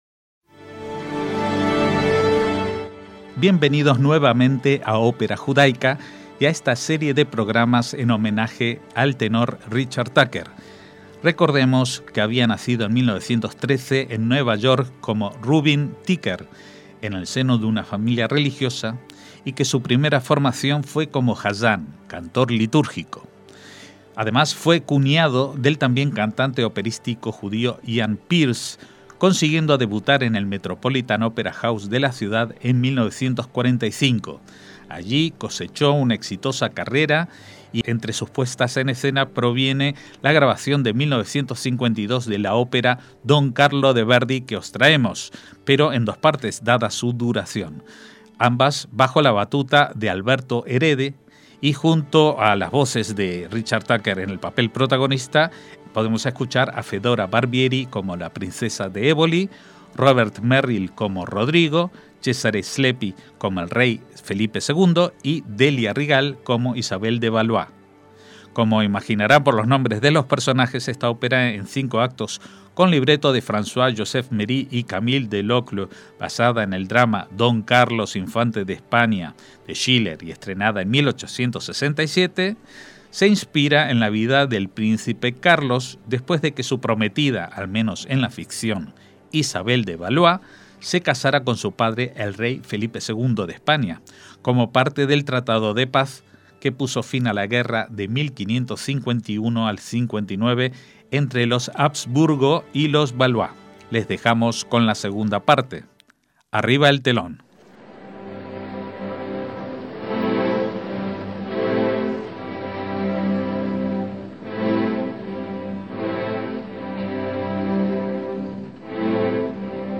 bajo la batuta de Alberto Erede y, junto a Richard Tucker en el papel protagonista, con las voces de Fedora Barbieri (como la princesa de Éboli)